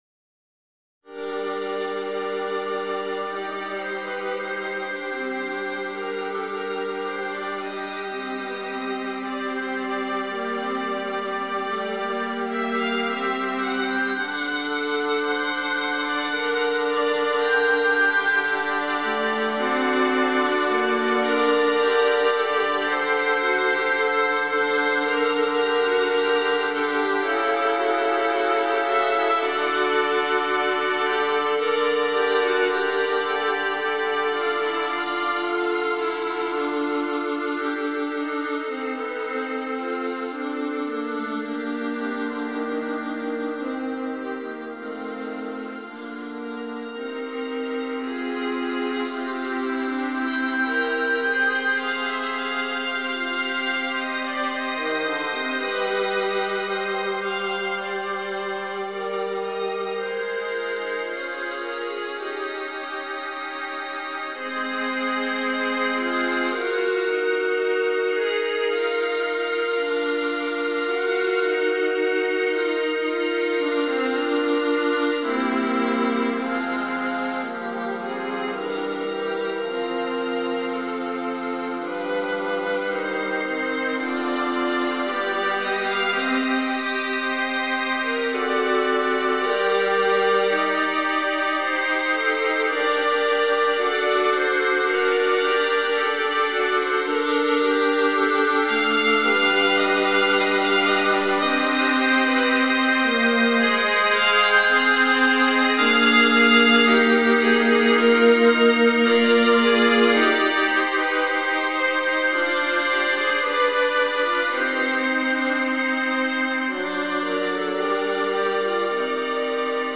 viola quartets